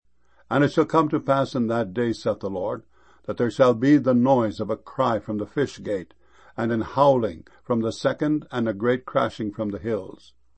crashing.mp3